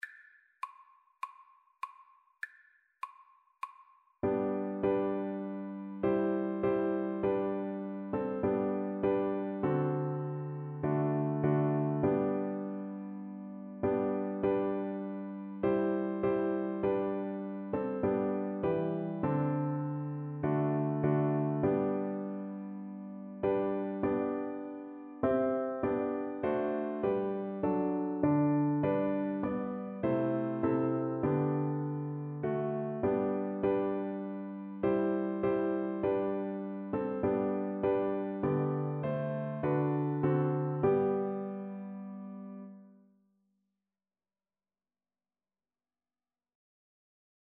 4/4 (View more 4/4 Music)
D4-E5
Classical (View more Classical Cello Music)